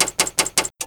IDG-A32X/Sounds/Cockpit/relay4.wav at 9cc0a1f785c69a21e3c66ba1149f7e36b5fb7c4d
relay4.wav